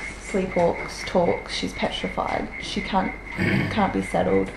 We captured EVP’s during our client interview prior to our equipment being fully setup.
EVP 1 – 1 minute into our client interview we hear this growl. The clients have no animals and we have recording of the neighbours dog barking and you can hear this in the distance however this growl is extremely loud and close to the recorder.
EVP5_growl.wav